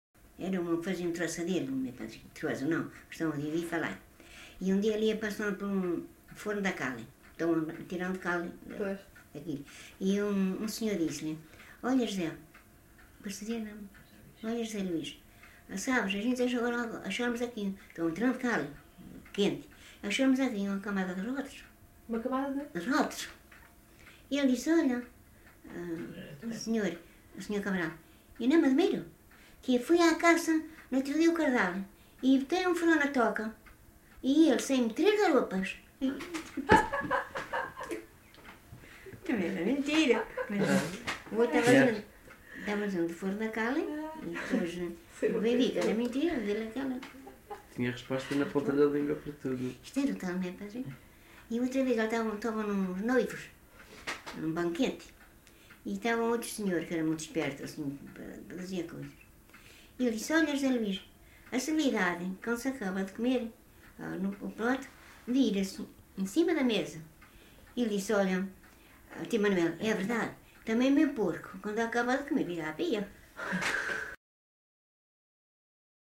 LocalidadeSanto Espírito (Vila do Porto, Ponta Delgada)